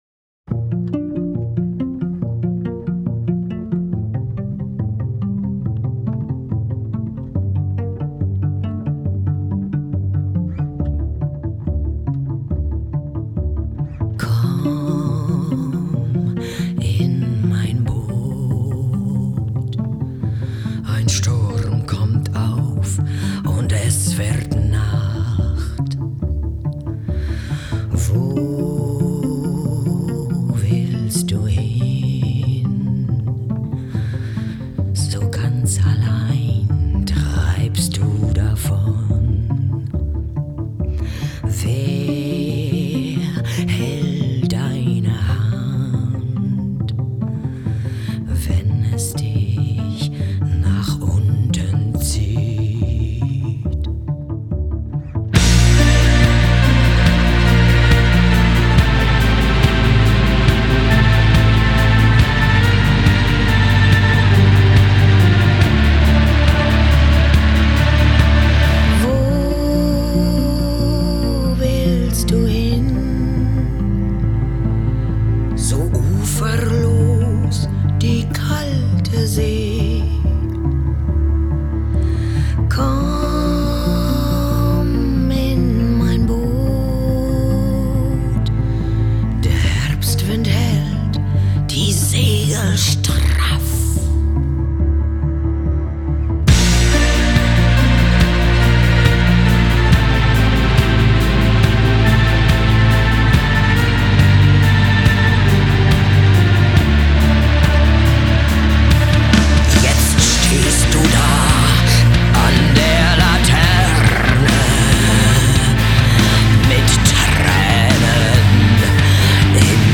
виолончелистами